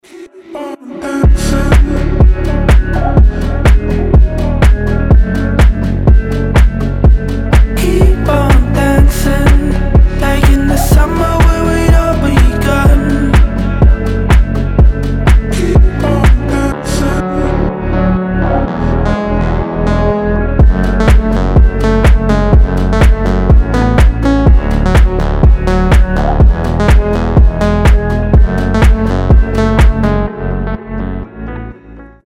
• Качество: 320, Stereo
красивые
deep house
мелодичные
Chill